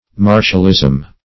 Search Result for " martialism" : The Collaborative International Dictionary of English v.0.48: Martialism \Mar"tial*ism\, n. The quality of being warlike; exercises suitable for war.
martialism.mp3